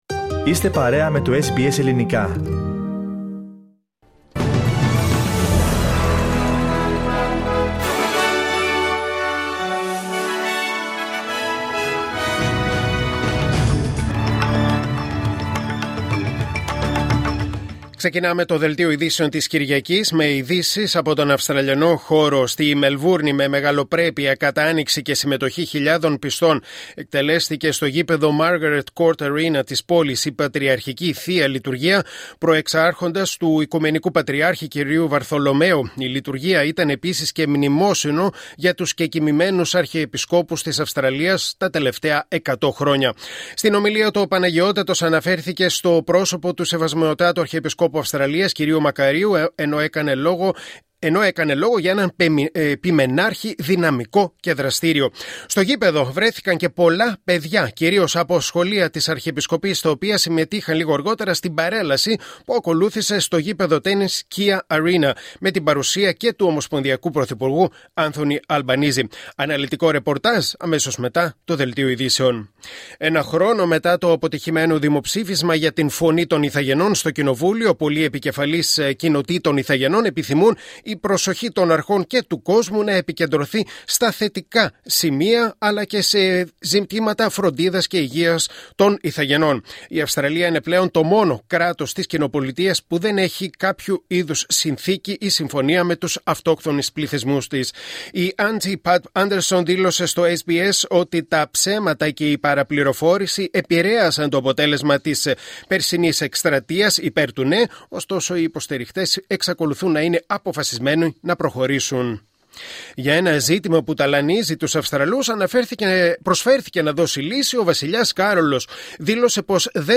Δελτίο Ειδήσεων Κυριακή 13 Οκτωβρίου 2024